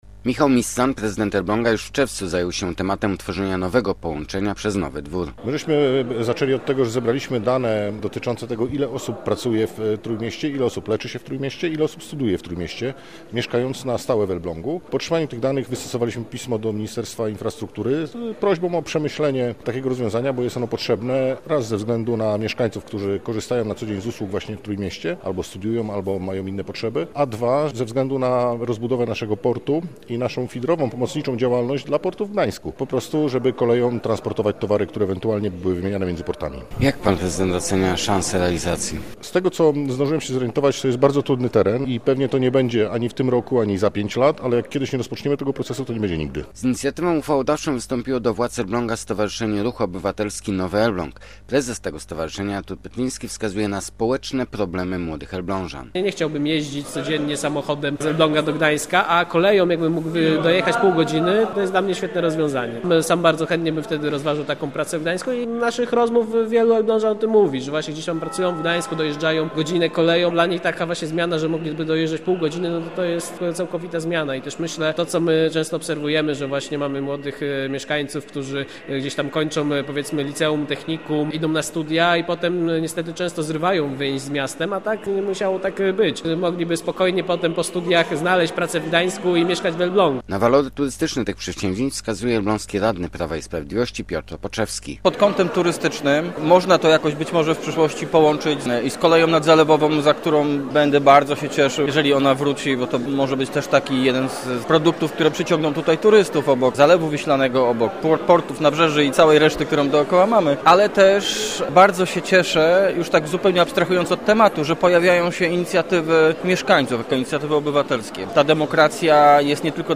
Posłuchaj materiału reportera: https